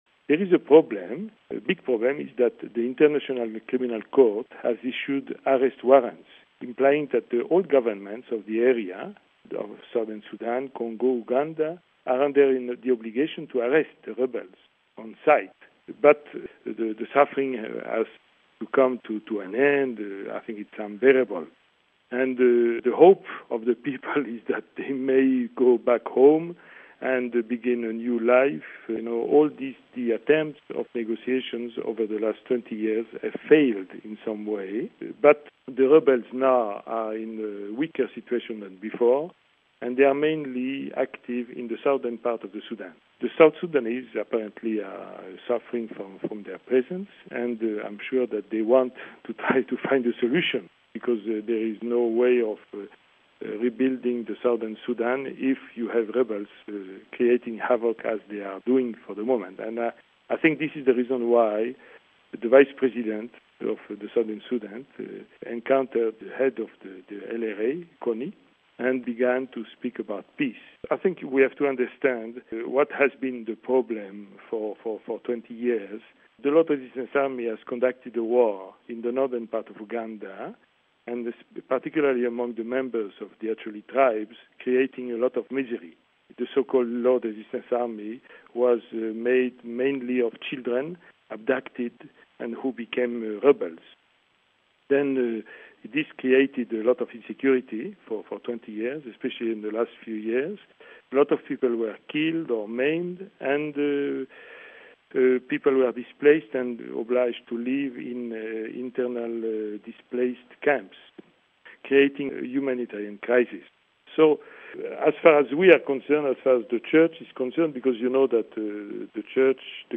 Some leaders of the LRA are wanted by a UN war crimes tribunal apostolic nuncio in uganda, which puts a strain on the dialogue as Apostolic Nuncio to Uganda, Archbishop Christophe Pierre, tells us...